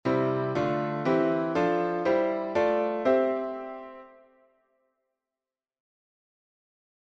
Gospel Piano